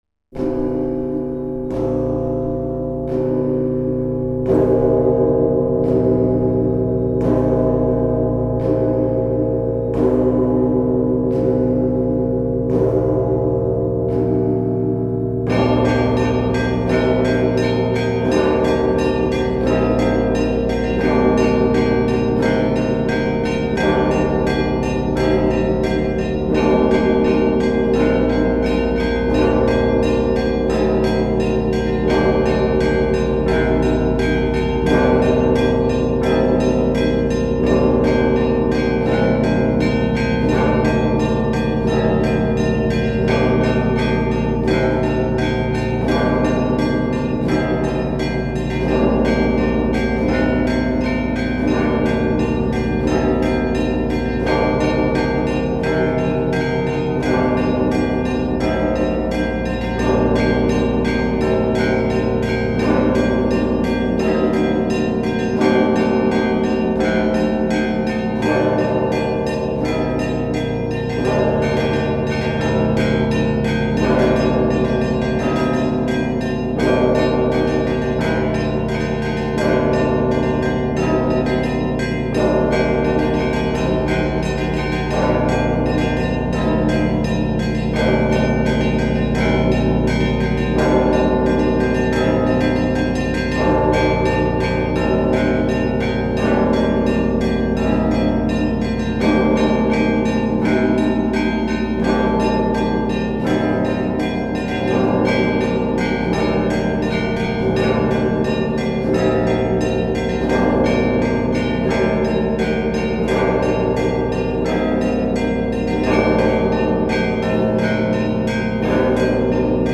Давайте сейчас послушаем звучание колокола. Вы услышите Большую звонницу Успенского кафедрального собора города Ростова.
Учитель: Какие чувства вызвал у вас колокольный звон?